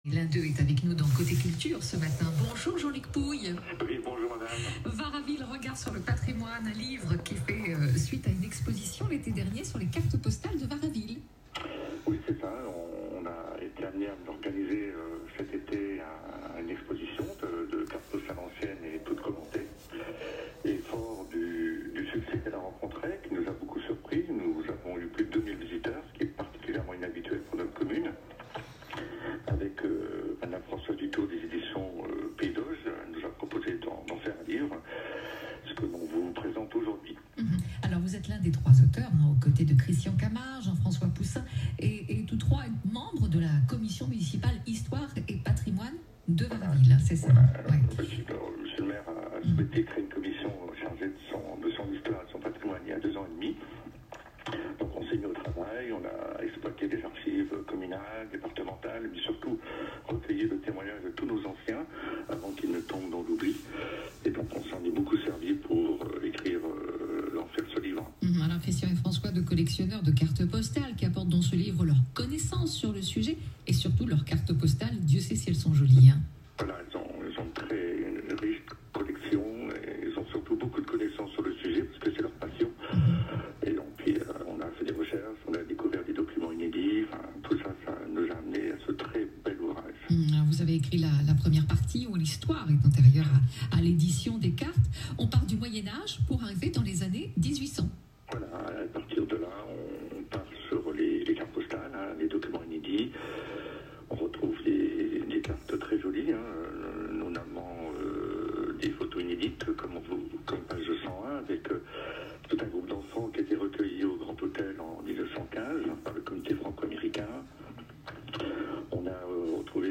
Interview France Bleu Basse-Normandie : cliquer ici